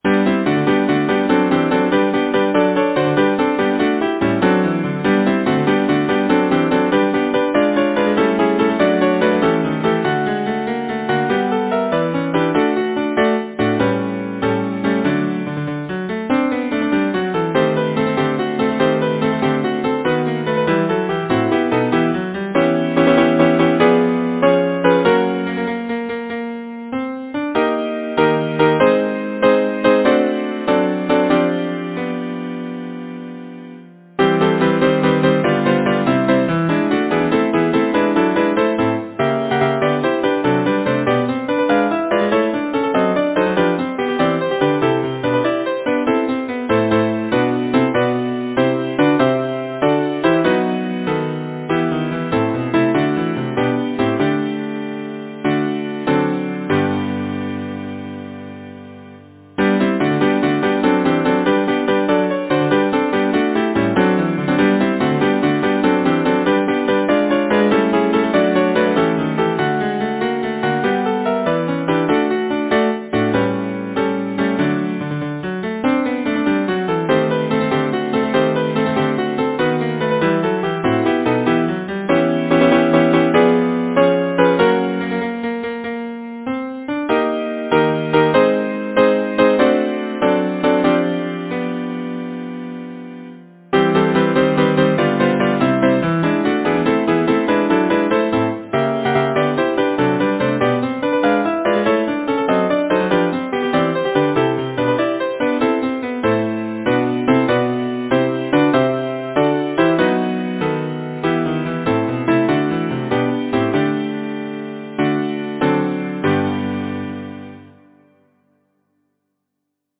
Title: The Butterfly Composer: Jacques Blumenthal Lyricist: Thomas Haynes Bayly Number of voices: 4vv Voicing: SATB, SB divisi Genre: Secular, Partsong
Language: English Instruments: A cappella